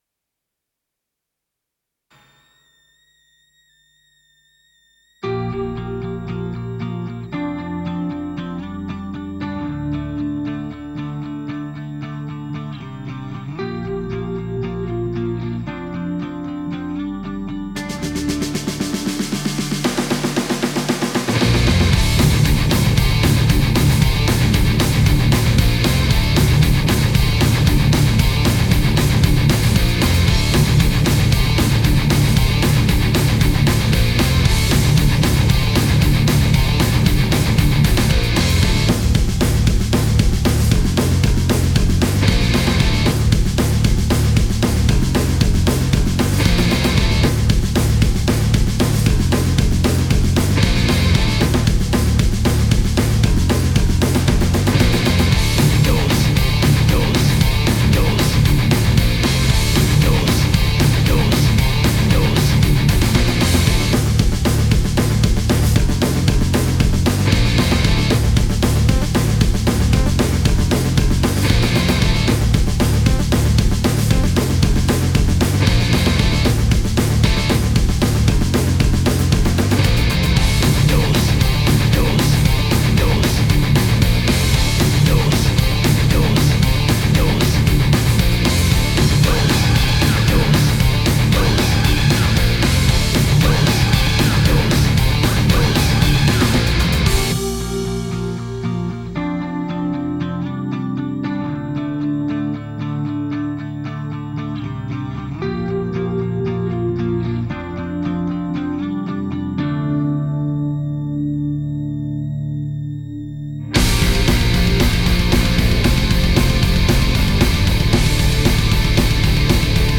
Больше интересуют низа.